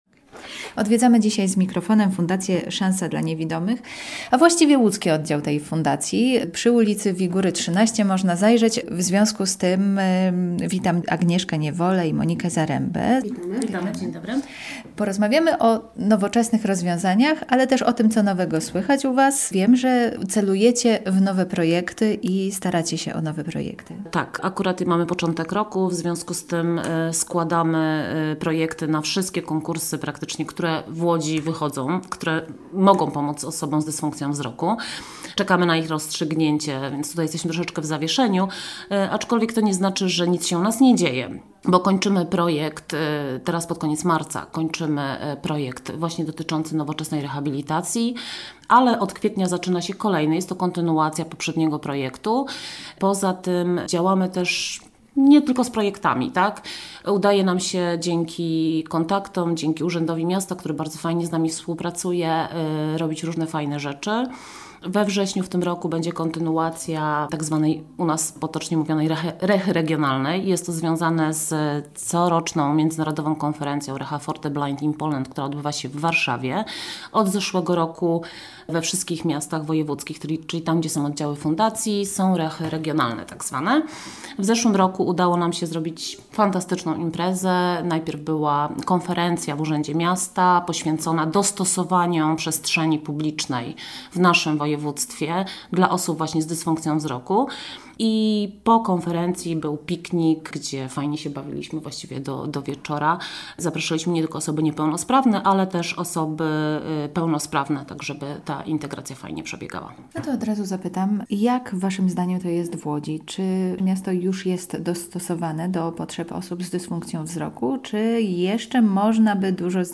Z mikrofonem odwiedzamy łódzki oddział fundacji Szansa dla Niewidomych.